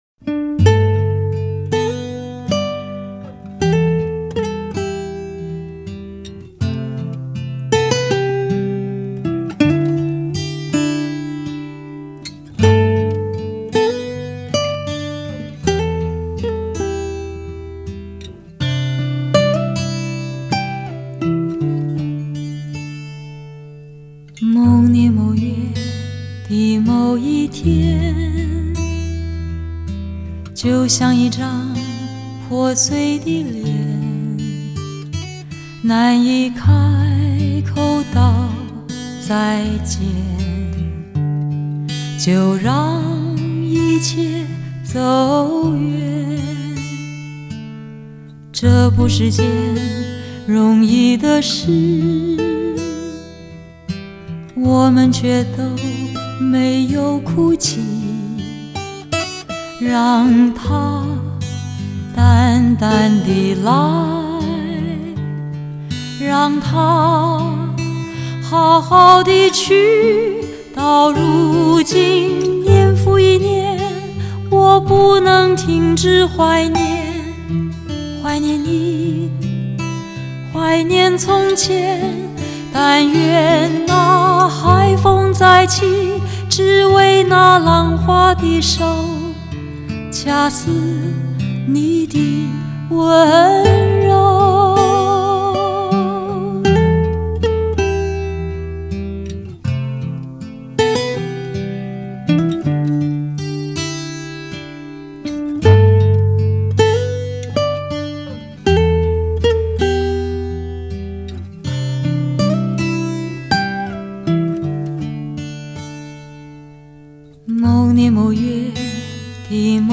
发烧试音碟
华丽惊艳 效果完美的鉴赏极品